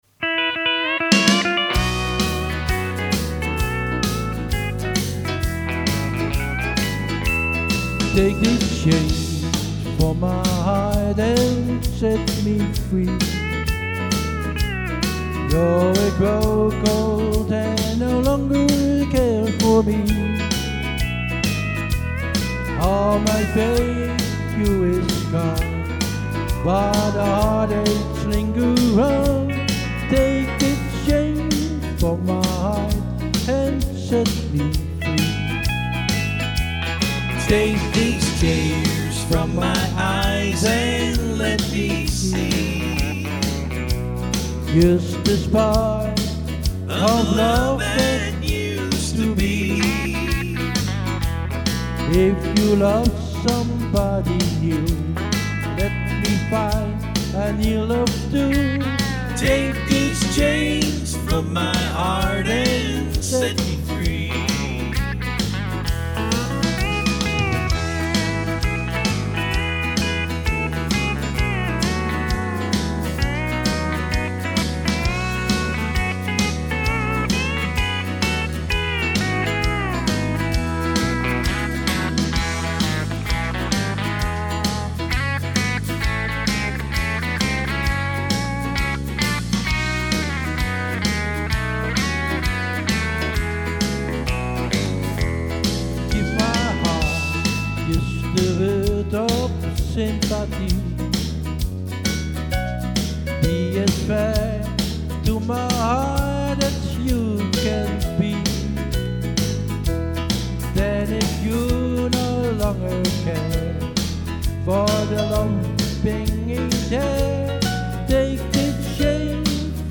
One-Man-Band
Zanger Toetsenist